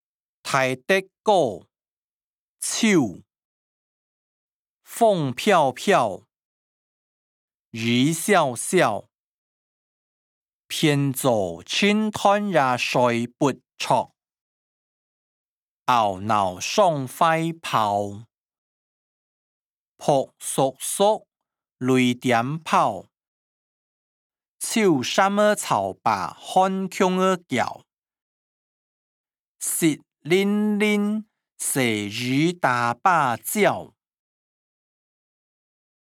詞、曲-大德歌‧秋音檔(海陸腔)